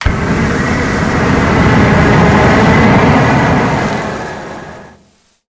flamethrower_shot_04.wav